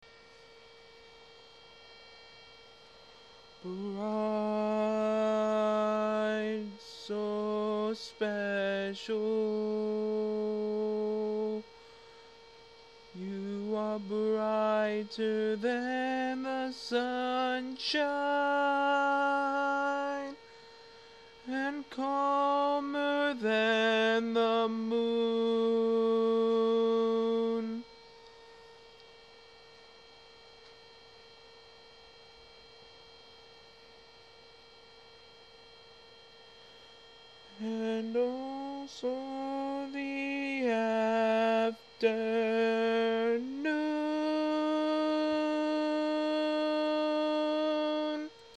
Key written in: F Major
Type: Barbershop
Each recording below is single part only.